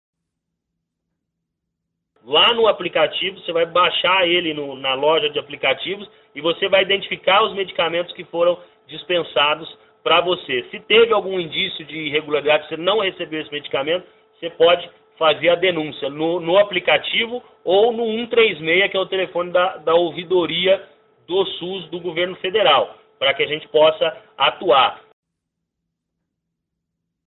O diretor do Departamento Nacional de Auditoria do SUS enfatizou que a população pode denunciar suspeitas de irregularidades.